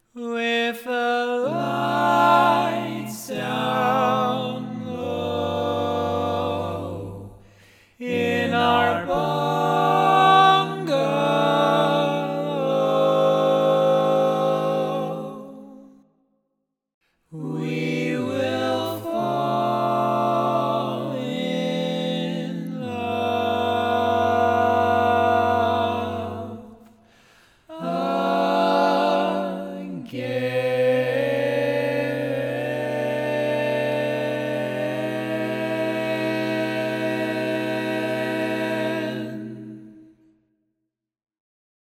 Key written in: C Major
How many parts: 4
Type: Barbershop
All Parts mix:
Learning tracks sung by